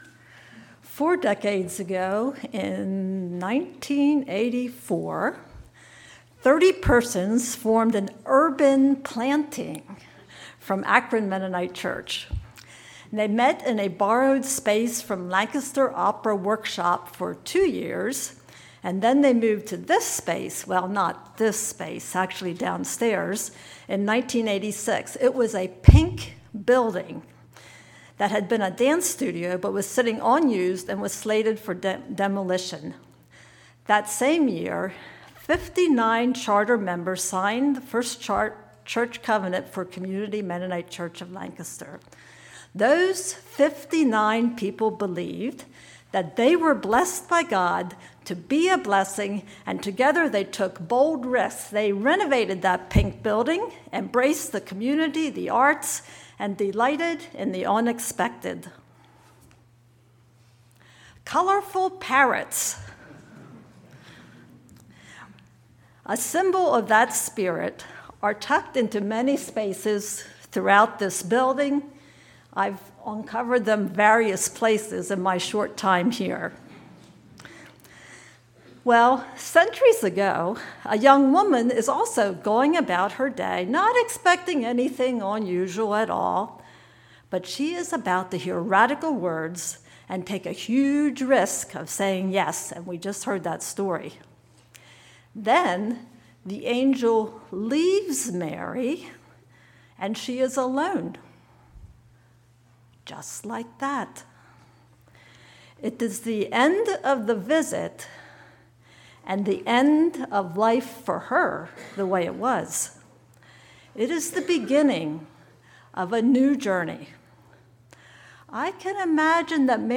12/1/24 Sermon